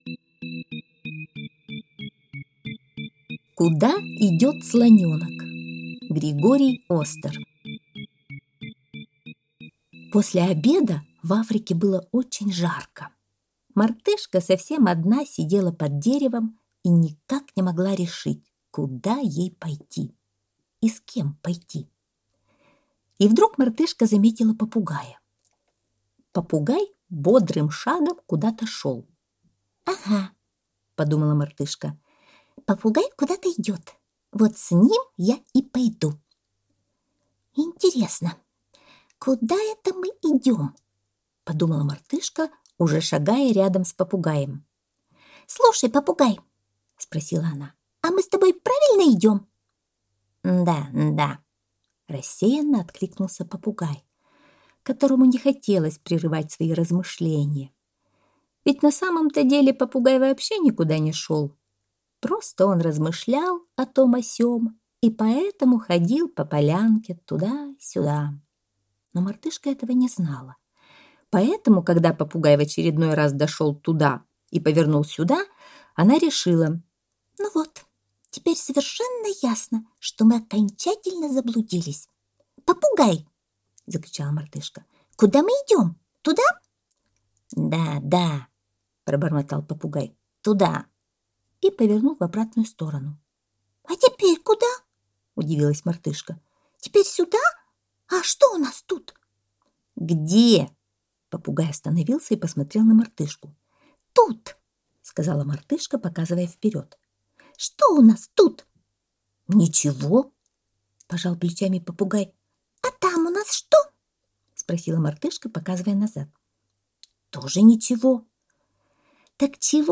Куда идёт слонёнок - аудиосказка Остера - слушать онлайн